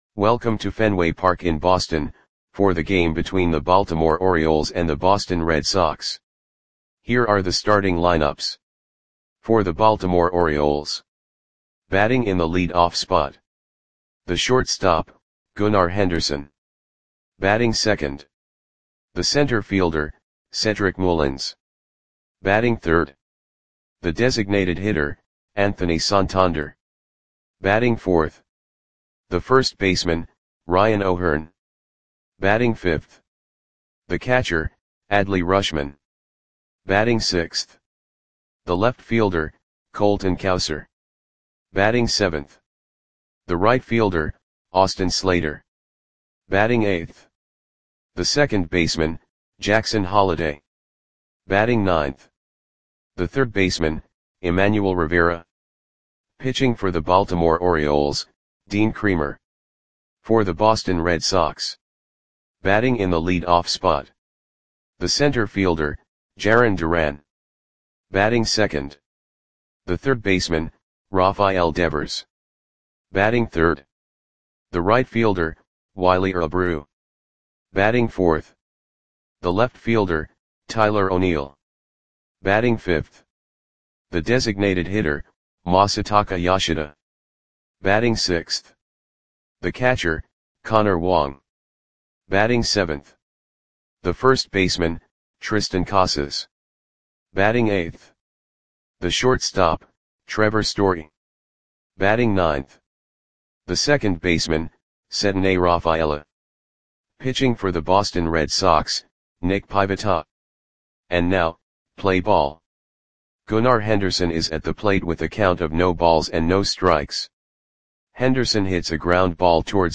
Audio Play-by-Play for Boston Red Sox on September 11, 2024
Click the button below to listen to the audio play-by-play.